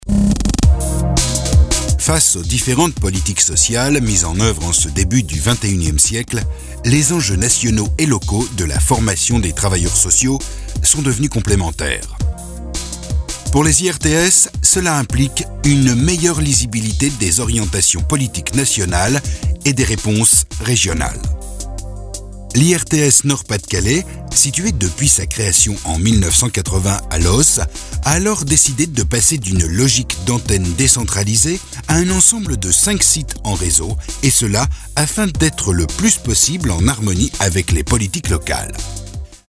Institut Régional du Travail Social NPDC 1 1 IRTS – Posée Formation aux métiers du travail social et médico-social, assistance technique, recherche et innovation, animation régionale.